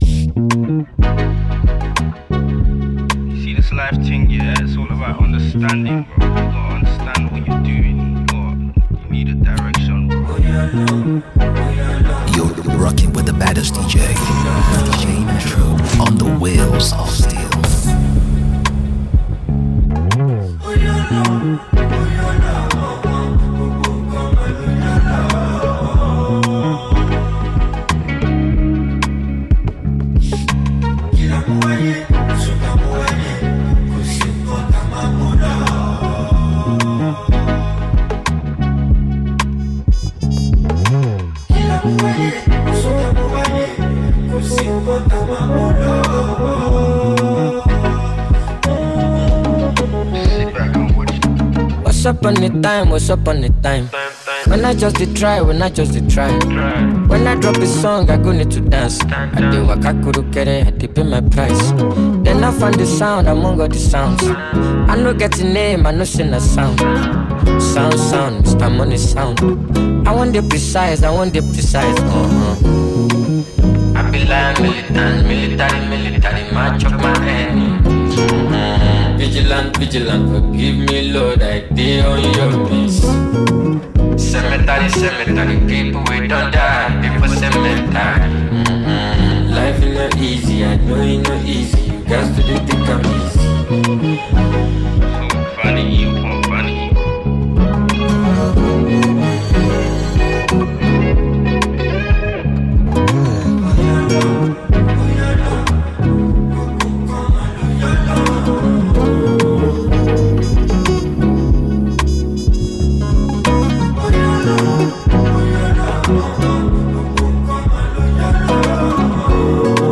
The Mix is a 53 minutes non-stop play of amazing music.